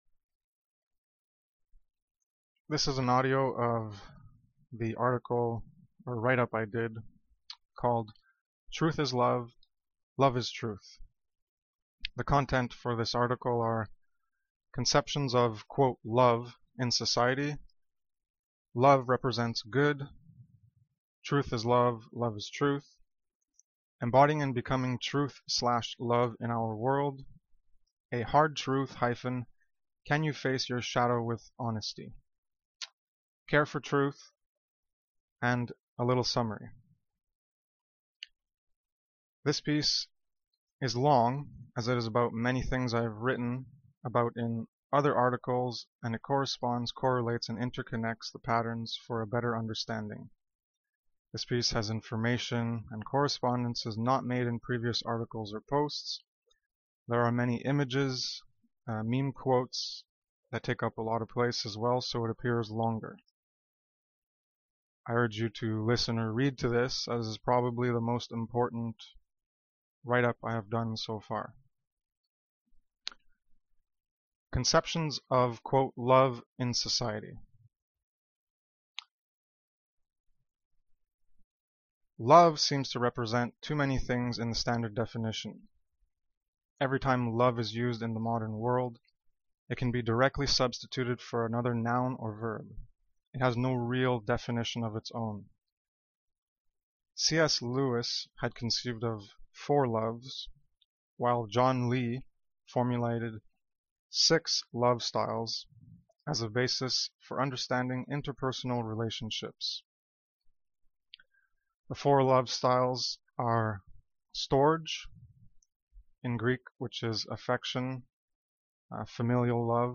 Audio file of the article (you will hear me lick my lips, I use a headset, read the article instead if that bothers you) There are some little bits of me providing additional explanation, just to clarify.